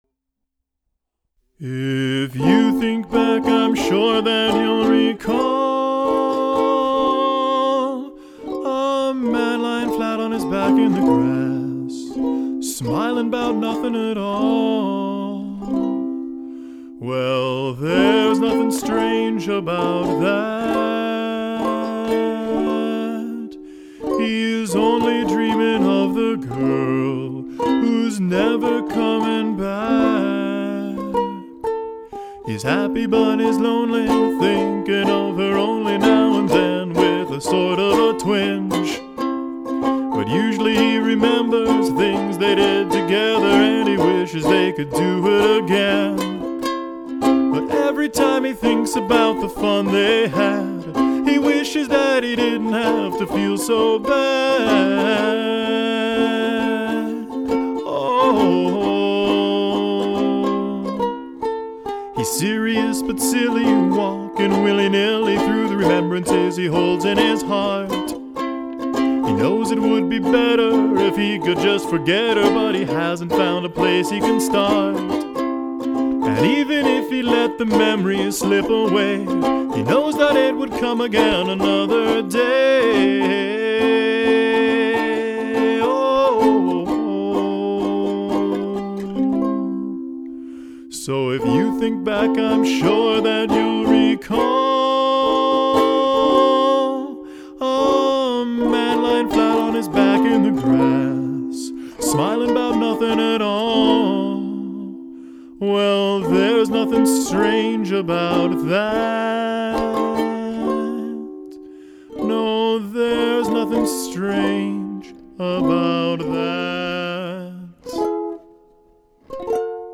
You'll hear a little Tin Pan Alley, if you listen close.